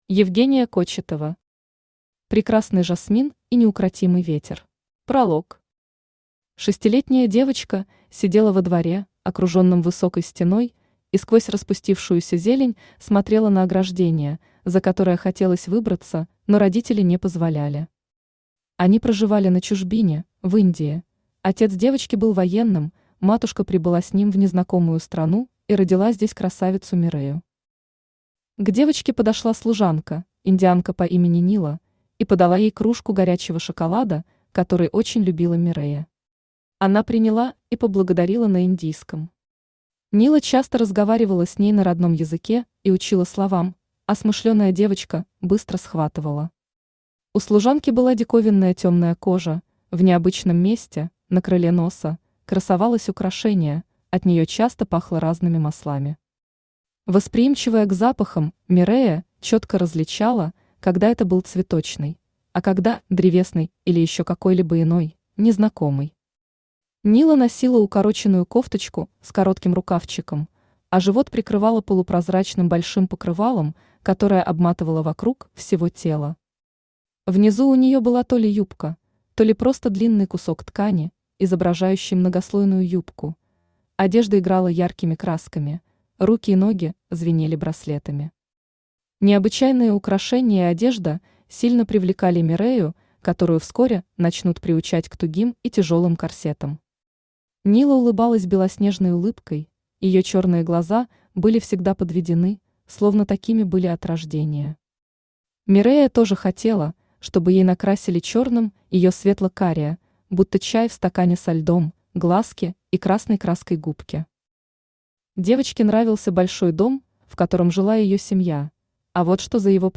Аудиокнига Прекрасный жасмин и Неукротимый ветер | Библиотека аудиокниг
Aудиокнига Прекрасный жасмин и Неукротимый ветер Автор Евгения Олеговна Кочетова Читает аудиокнигу Авточтец ЛитРес.